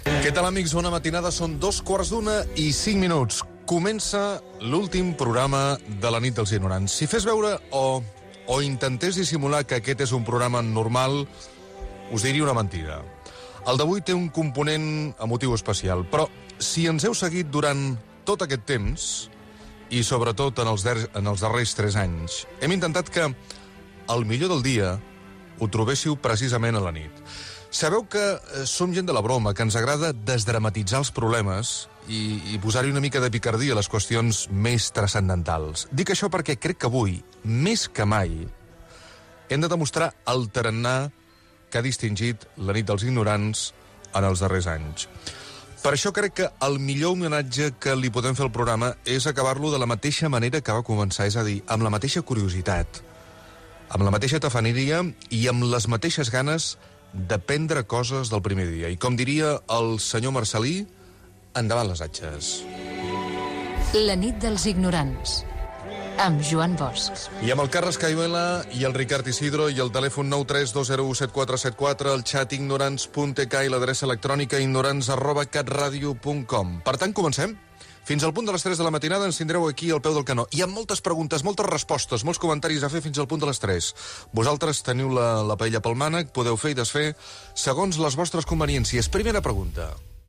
indicatiu del programa, equip i formes de contactar amb el programa